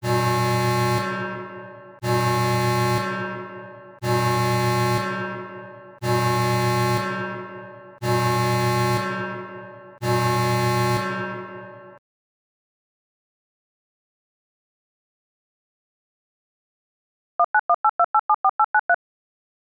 warnSound.aiff